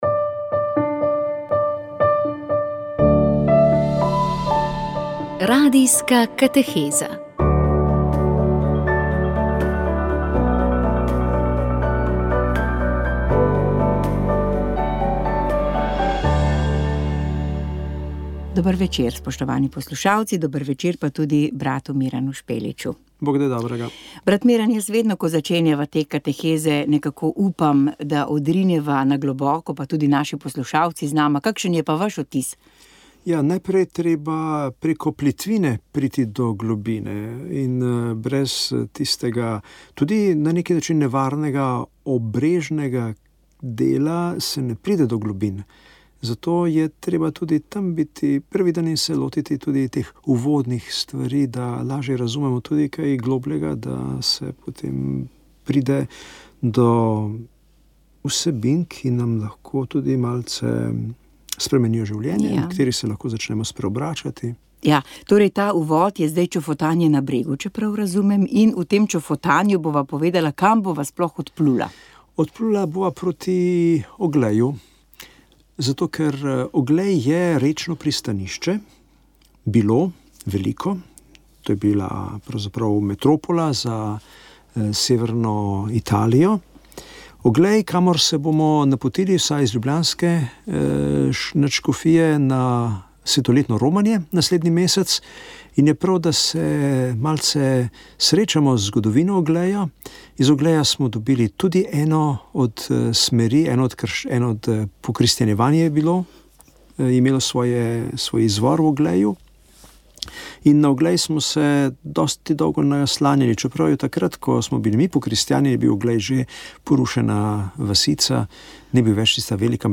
Frančišek Asiški kontemplativna molitev kateheza Radijska kateheza VEČ ...